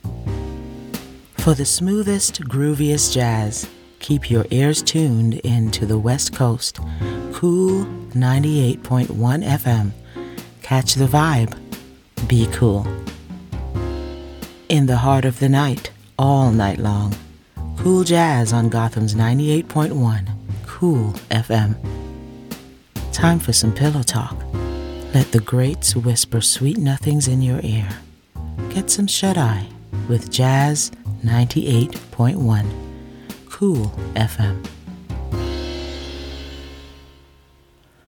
Inglés (Caribe)
Imágenes de radio
Estudio: Estudio profesional en casa con tratamiento acústico para audio seco de calidad de emisión.
ContraltoProfundoBajo